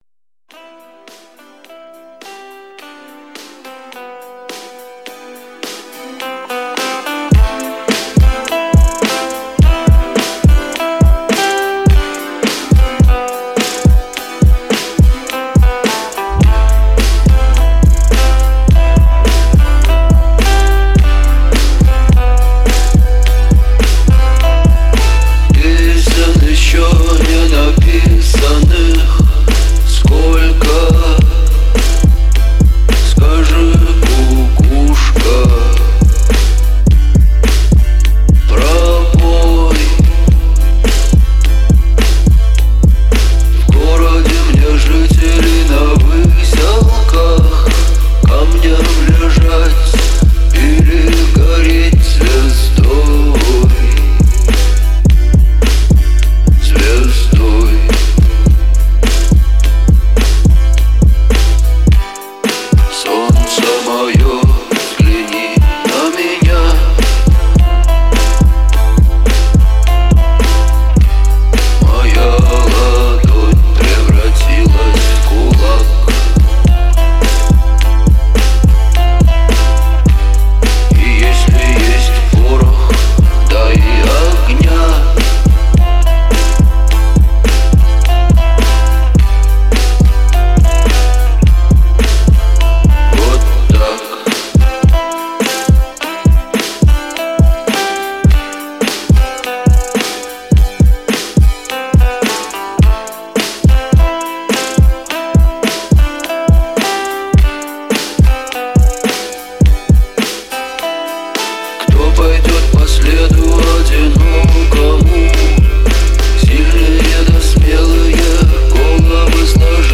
Для тренировок